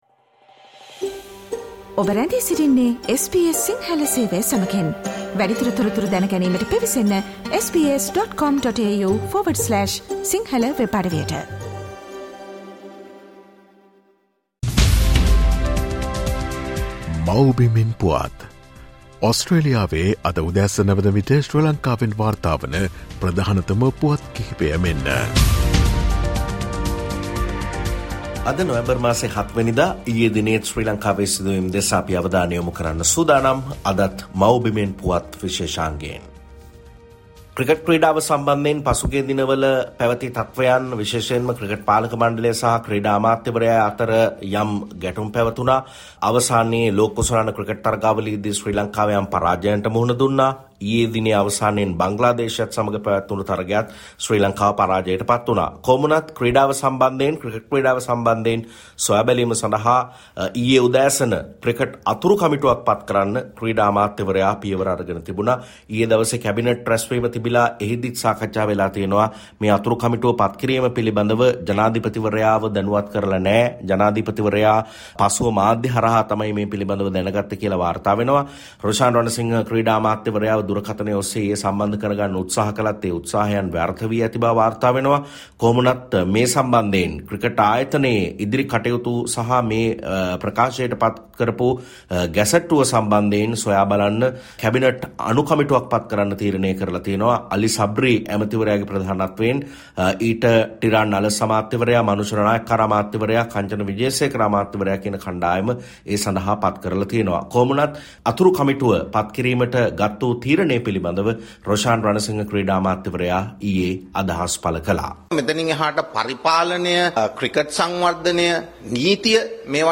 SBS Sinhala featuring the latest news reported from Sri Lanka - Mawbimen Puwath